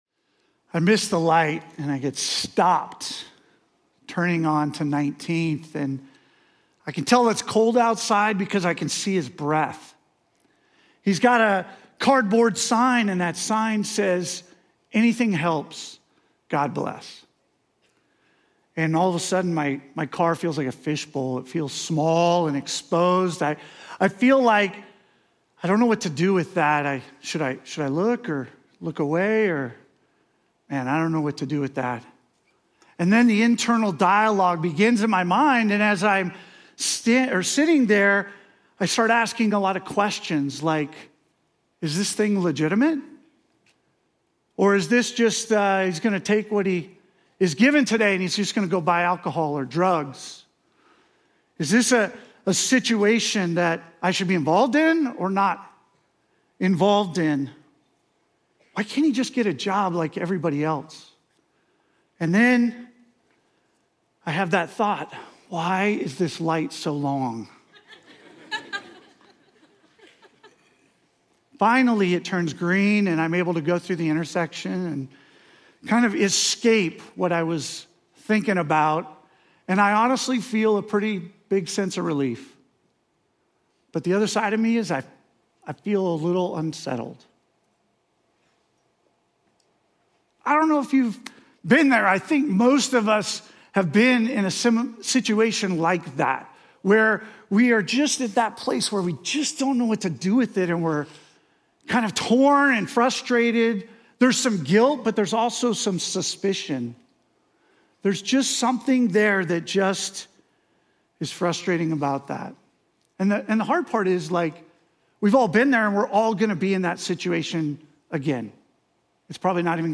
What's one thing that stood out to you from the sermon and why? 2.